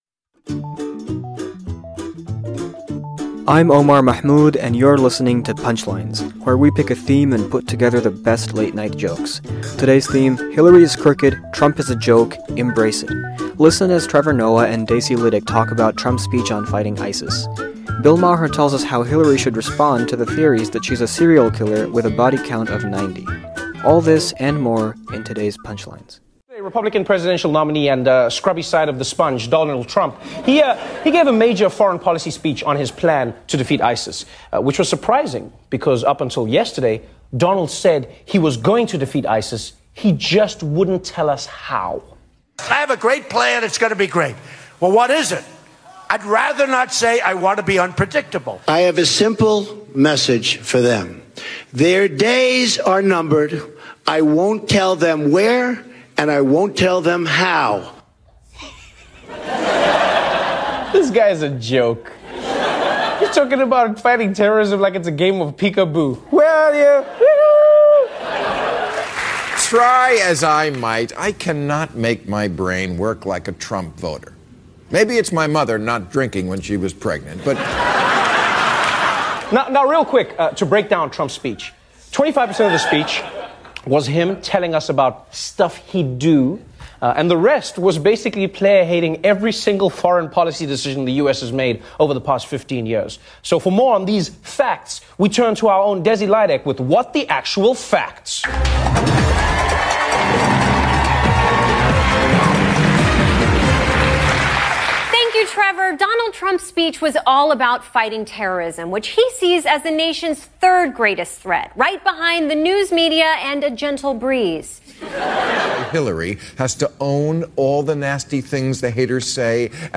The late-night comics point out the flaws (real or perceived) about our presidential candidates.